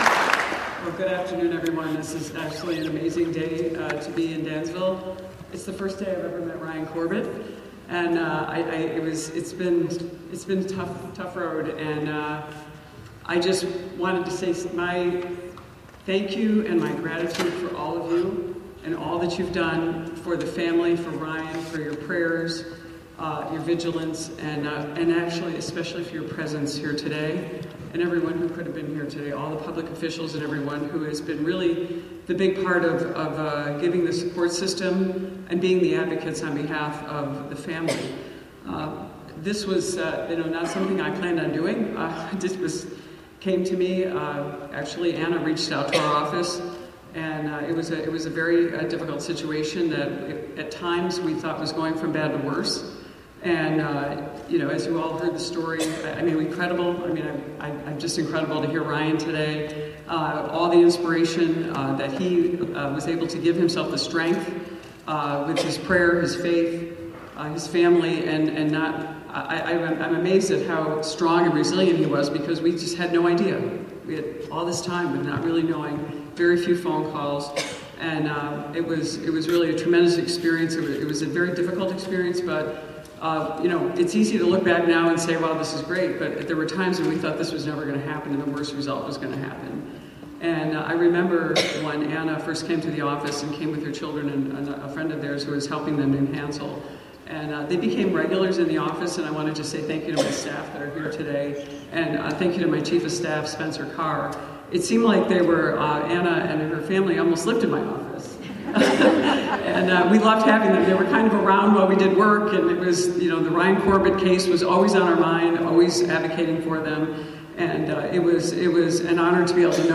The congresswoman also spoke at Saturday’s Homecoming Celebration.